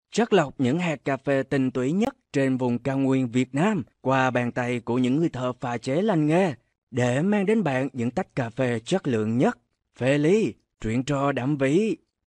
越南语样音试听下载
越南语配音员（男4）
VN-DV006-male-demo.mp3